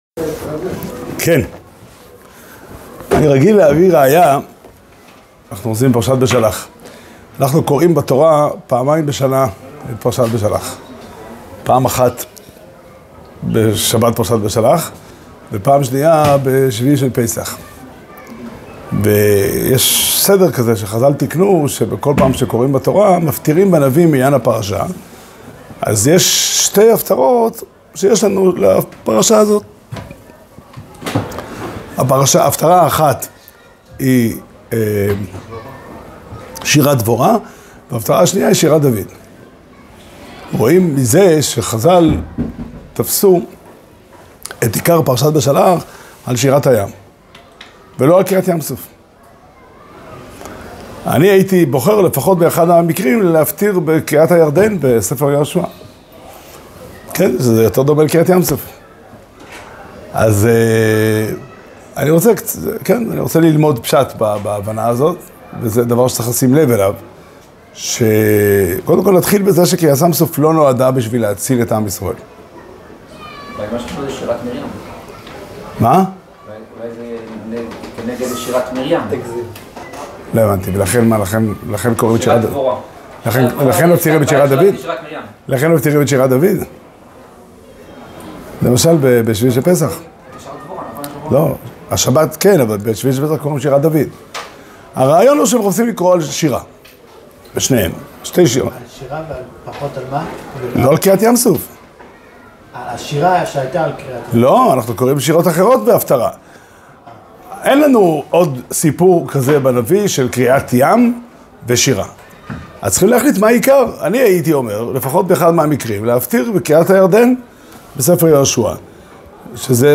שיעור שנמסר בבית המדרש פתחי עולם בתאריך ה' שבט תשפ"ה